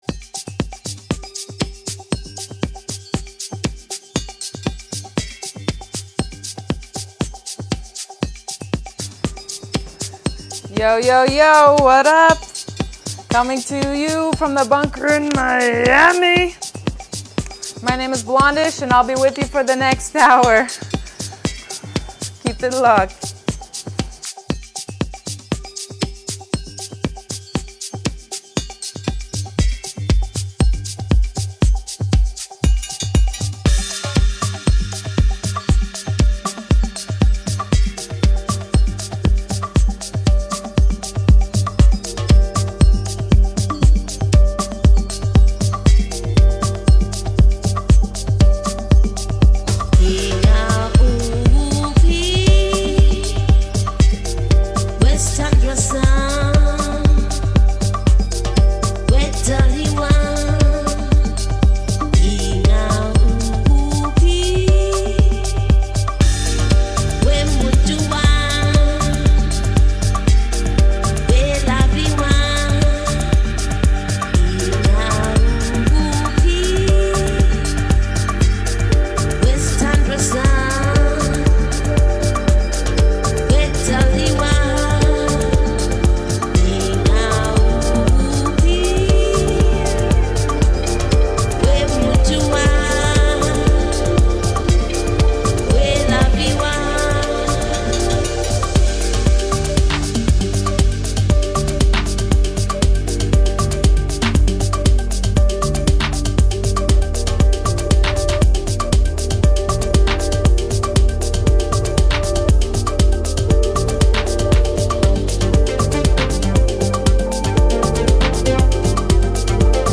Genre: House